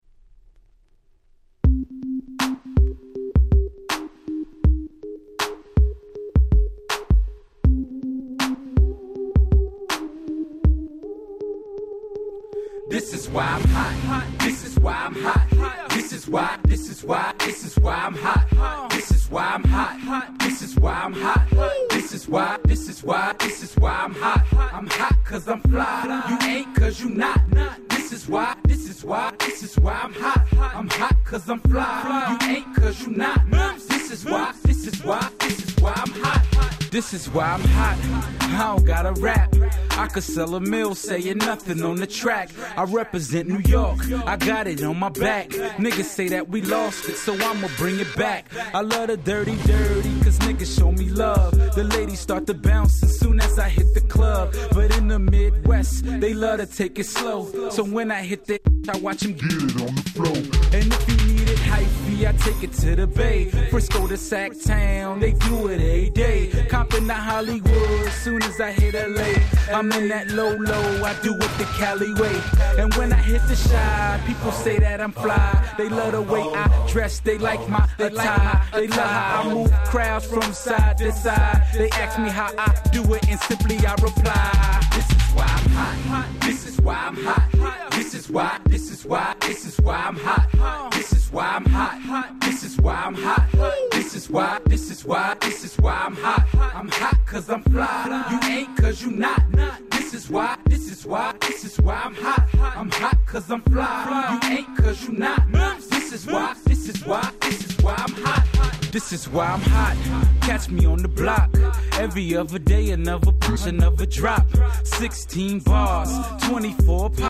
06' Super Hit Hip Hop !!